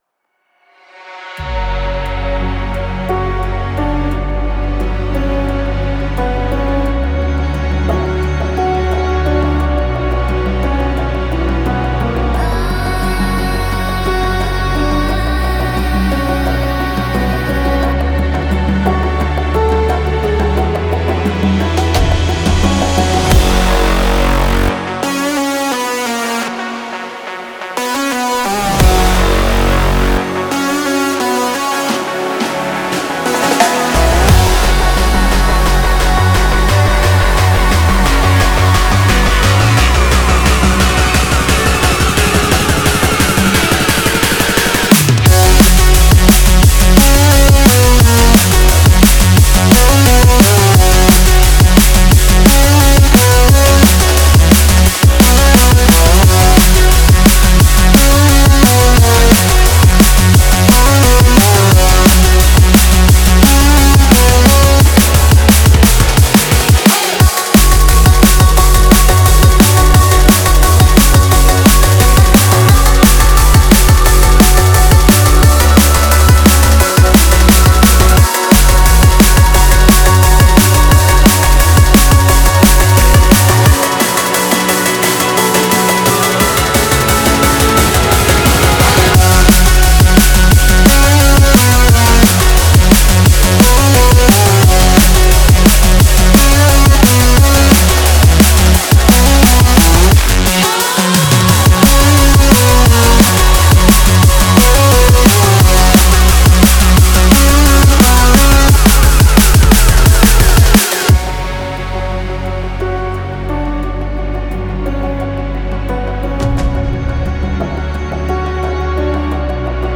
Drum & Bass, Dark, Dreamy, Epic, Energetic, Weird